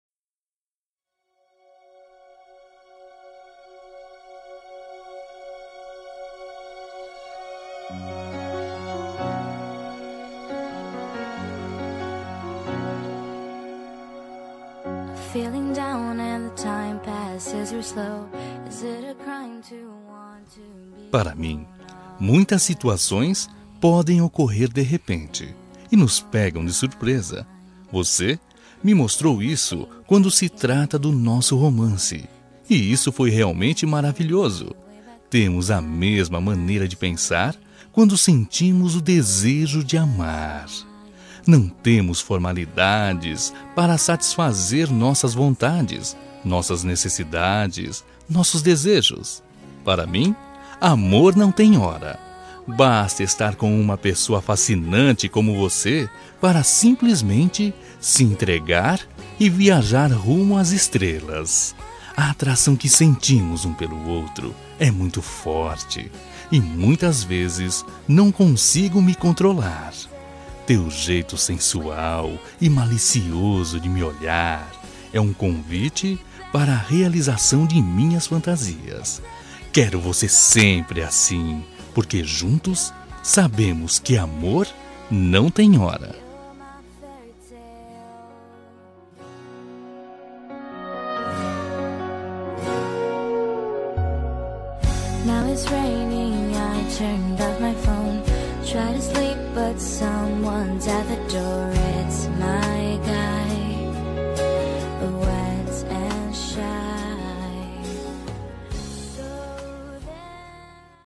Voz Masculina